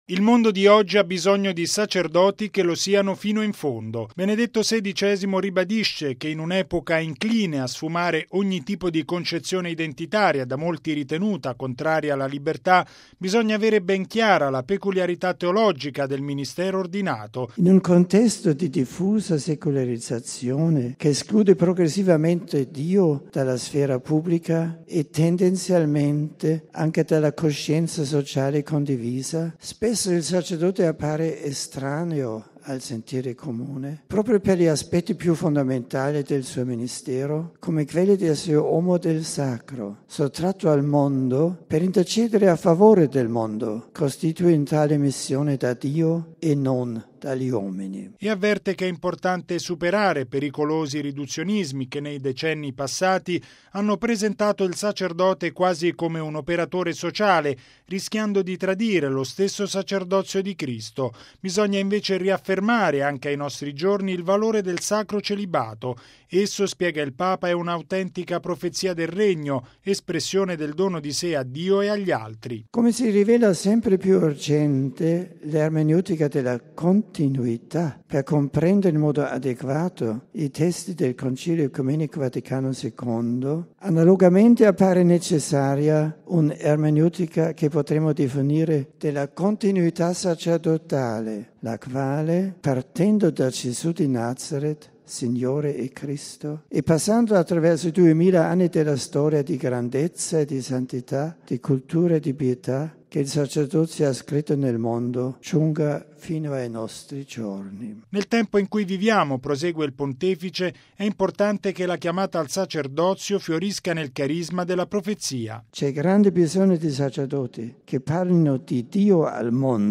◊   C’è bisogno di sacerdoti che, senza seguire le mode culturali, testimonino la presenza di Dio al mondo: è il cuore dell’appassionato discorso che Benedetto XVI ha rivolto stamani ai 700 partecipanti, tra vescovi e sacerdoti, al Convegno teologico promosso dalla Congregazione per il Clero.
Il servizio